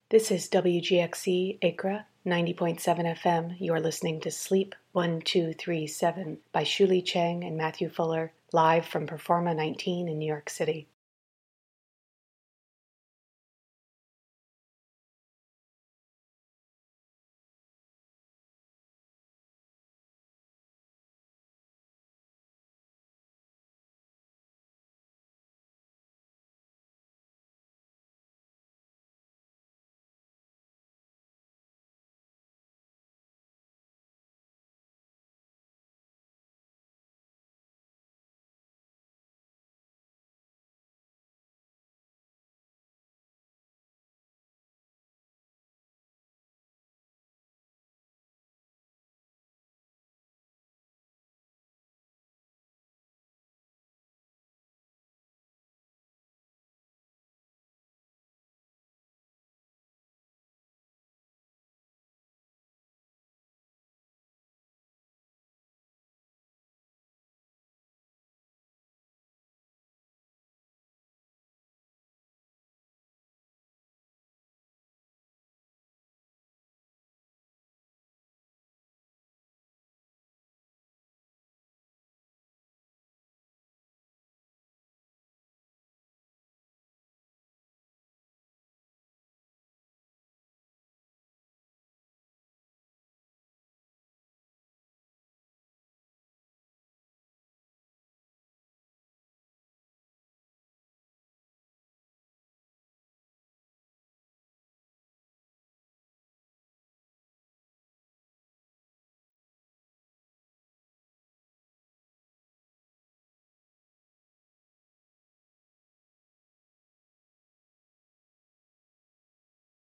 Station ID for PERFORMA 19 Sleep1237 (Audio)
Station ID appears at the top of the hour and is 11 second in duration; the rest of this hour-long file is silent.